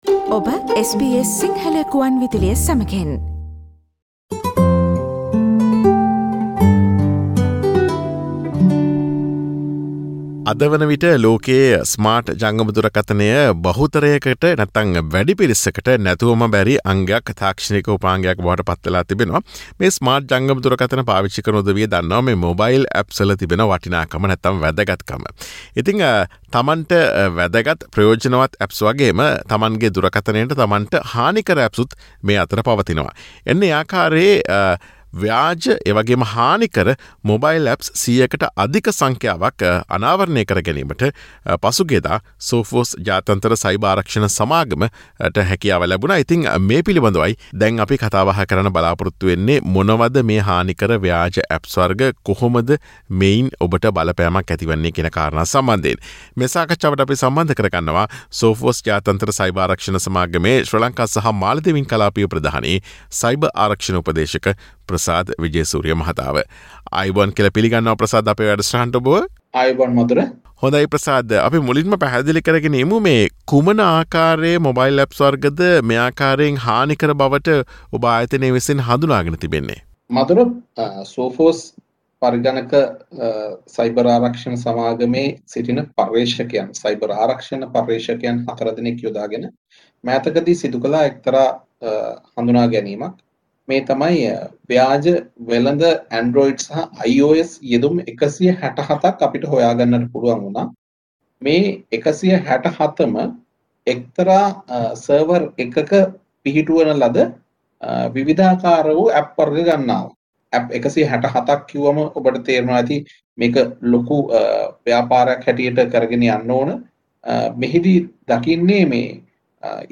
SBS Sinhalese discussion on this topic